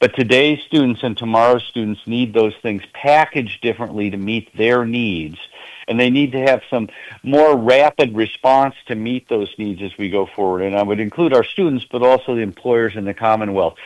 At yesterday’s Council of Trustees meeting, IUP President Michael Driscoll said that the university will need to think outside the box when it comes to delivering education to the students.